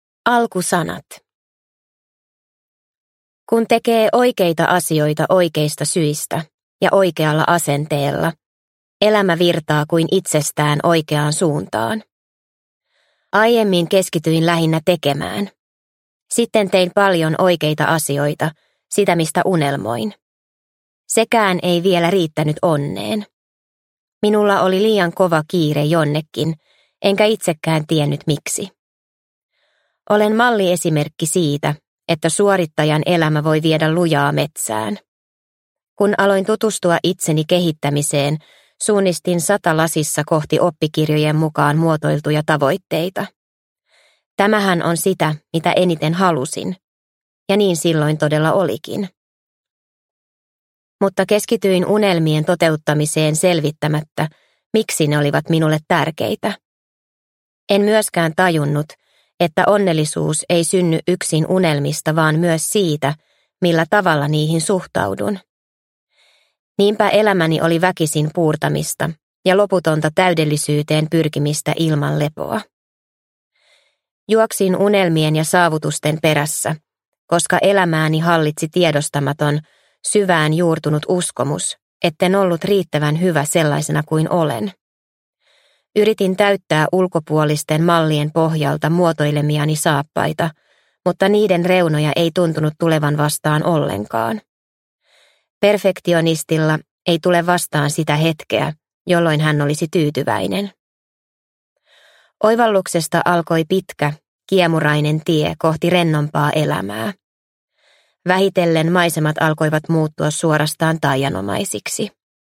Löydä elämän taika – Ljudbok – Laddas ner